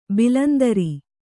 ♪ bilandari